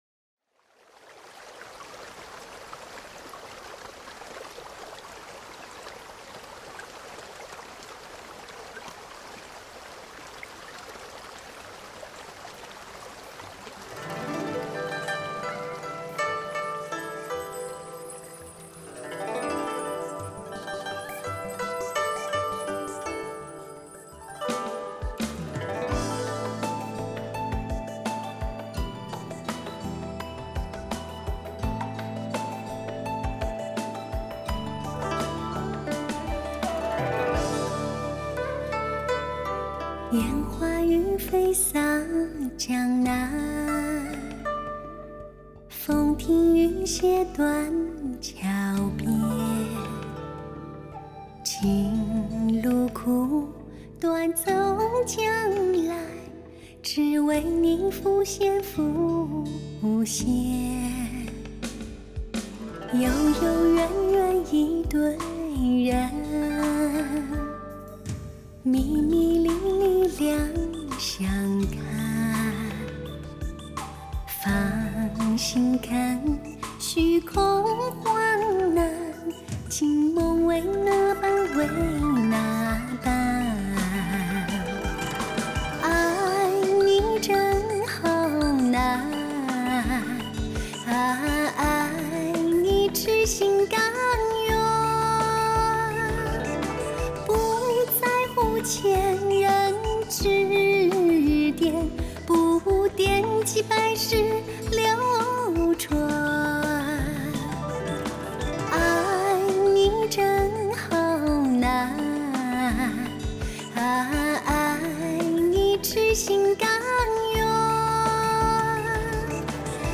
史上人声最甜美、感情最丰富的女声。